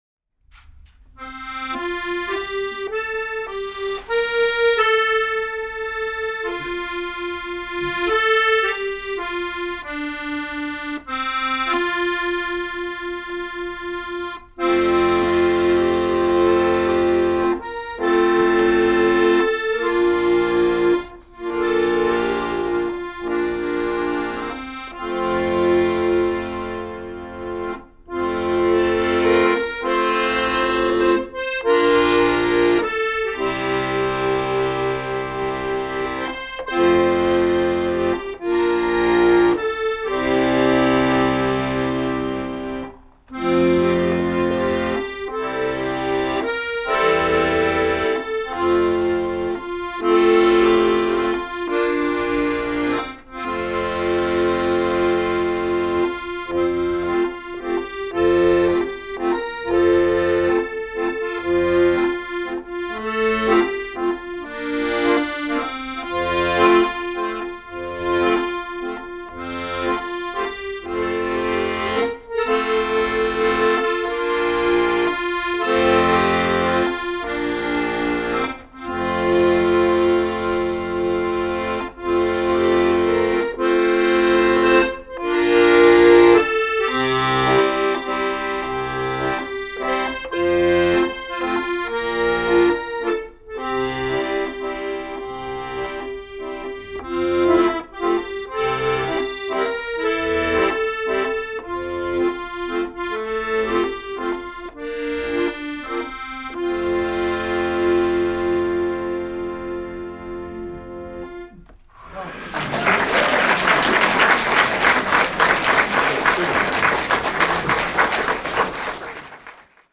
2025 Concert Recordings  - Wisdom House, Litchfield, CT